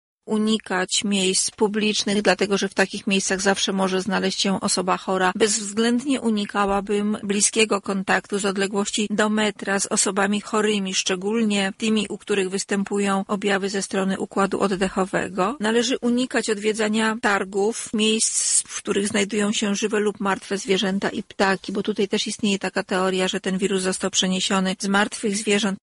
Zapytaliśmy Lubelska Państwową Wojewódzką Inspektor Sanitarną Irminę Nikiel, jakie środki ostrożności zachować, kiedy jednak zdecydujemy się na taki wyjazd: